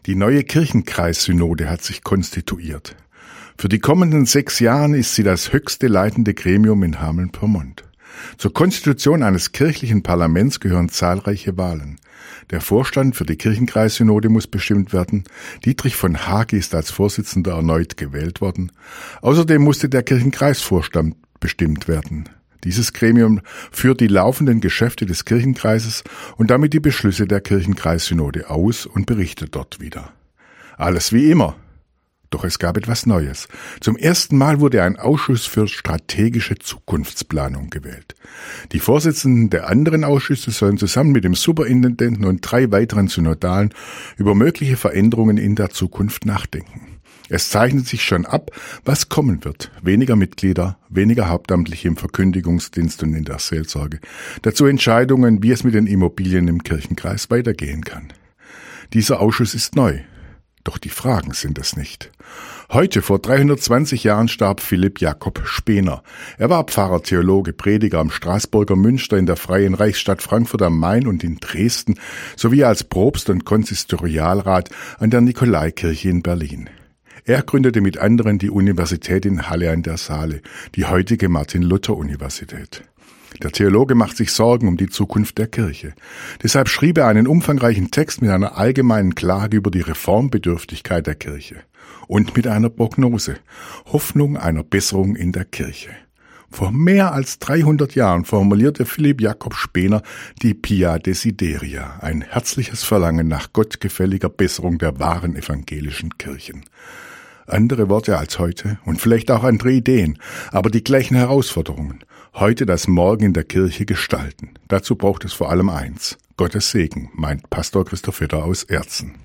Radioandacht vom 05. Februar